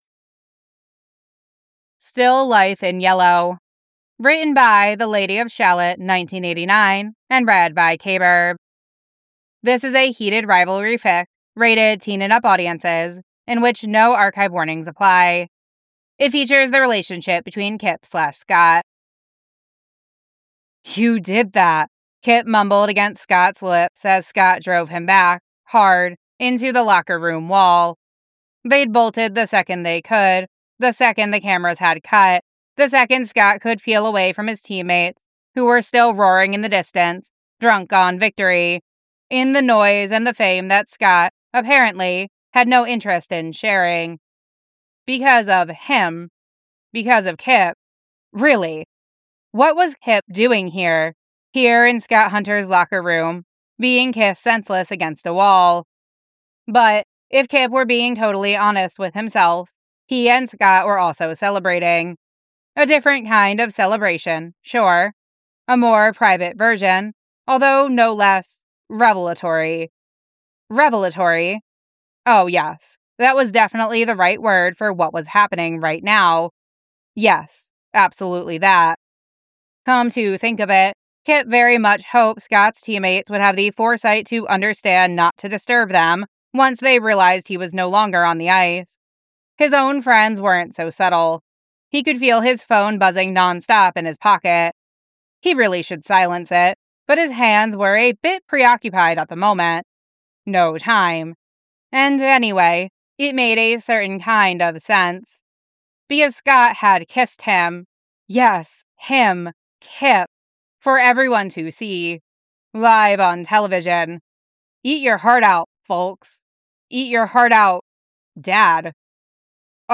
No Music